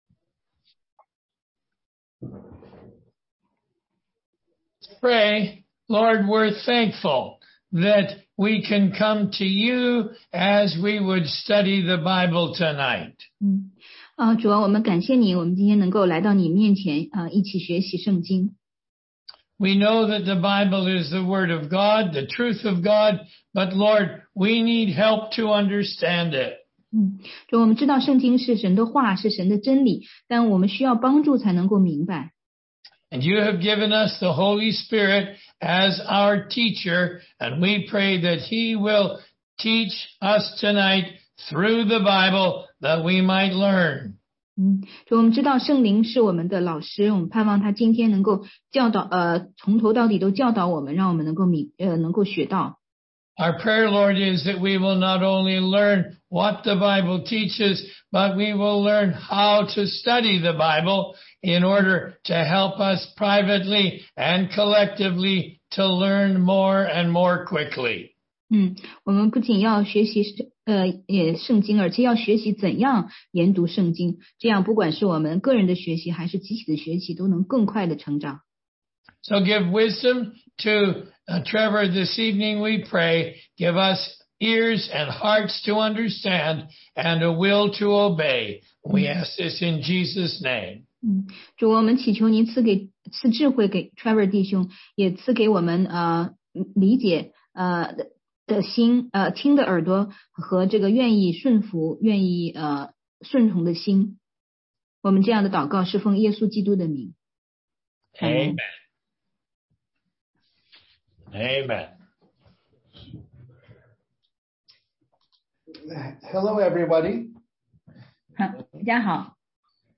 16街讲道录音 - 读经的原则和方法系列之十：见证的重复